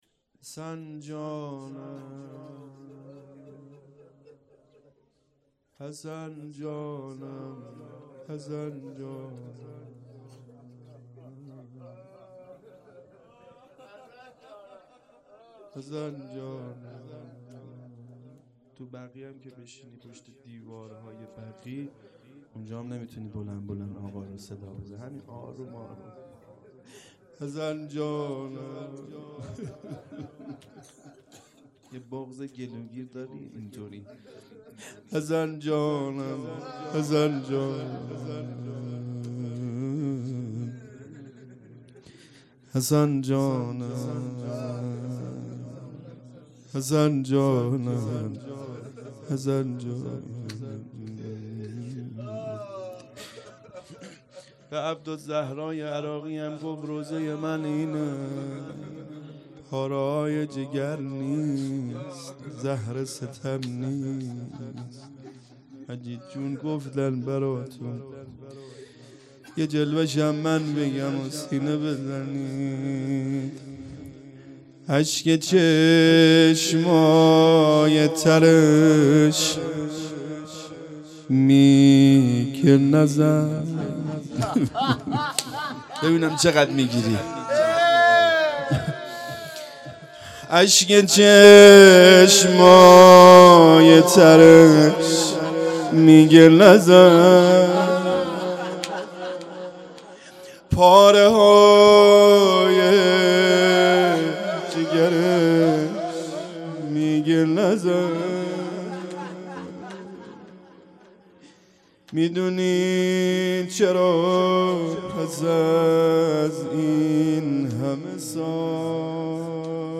روضه
اقامه عزای رحلت پیامبر اکرم و شهادت امام حسن مجتبی علیه السلام